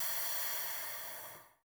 T BREATH 2.wav